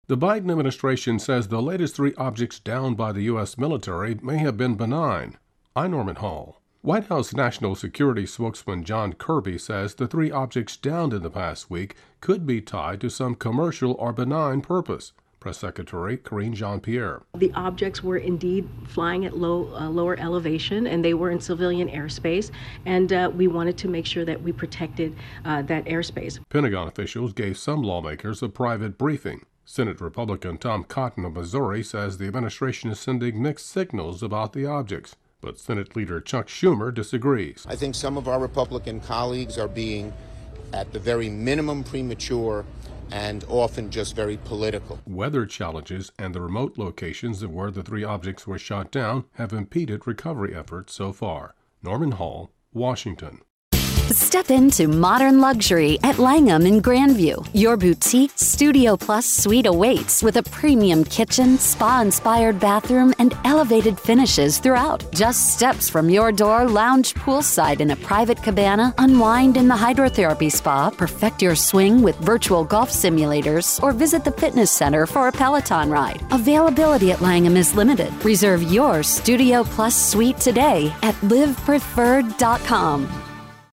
AP correpondent